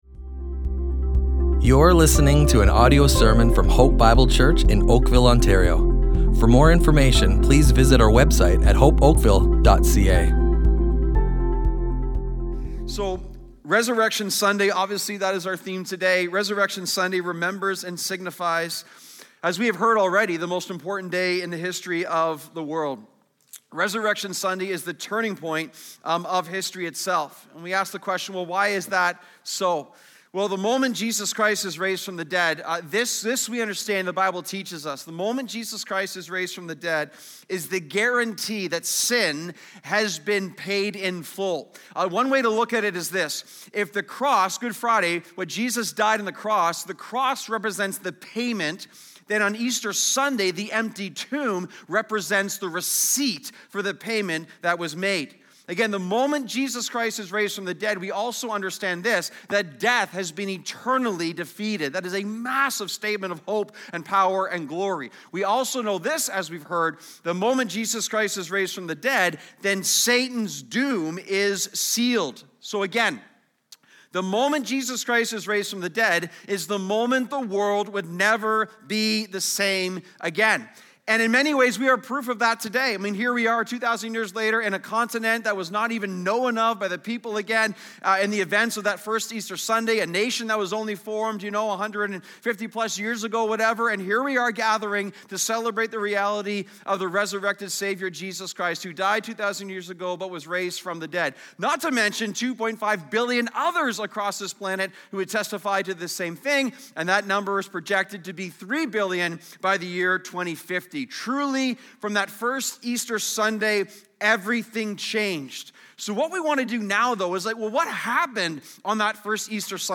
Hope Bible Church Oakville Audio Sermons Easter 2025 // Hope Resurrected!